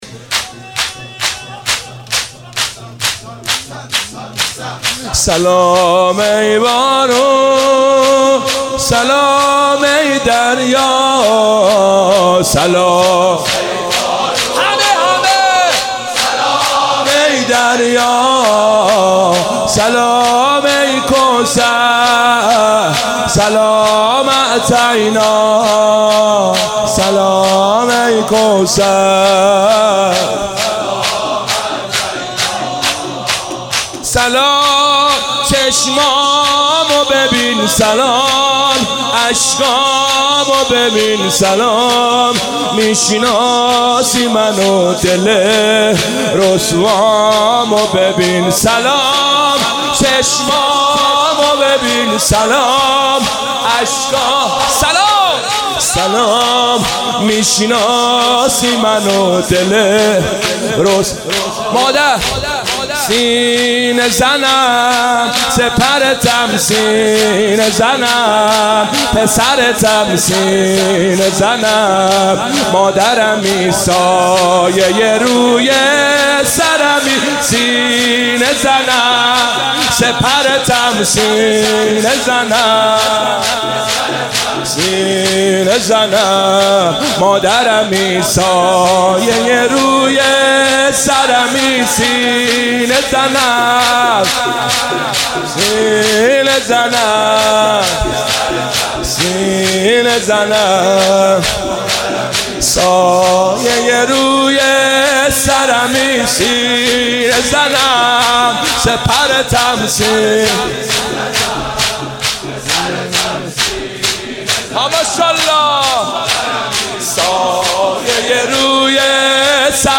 ایام فاطمیه 95 - 30 بهمن 95 - شور - سلام ای بانو سلام ای دریا
خیمه حضرت فاطمه زهرا سلام الله علیها